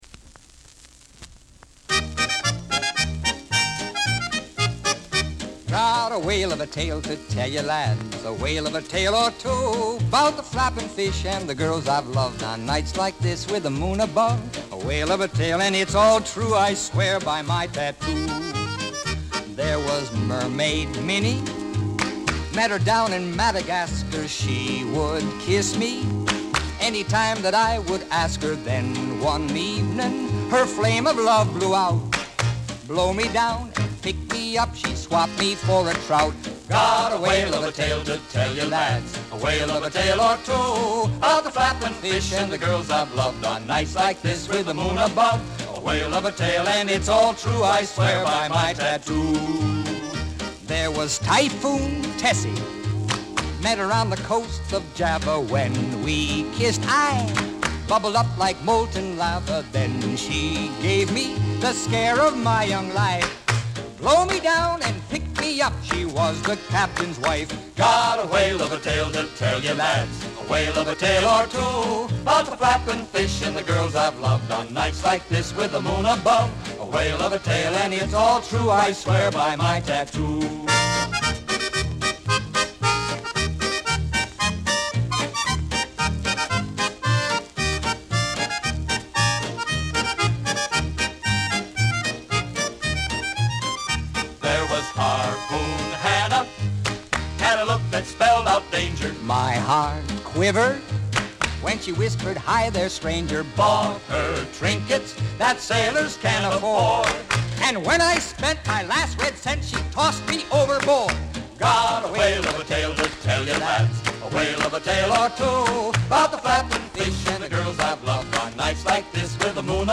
45 RPM Vinyl record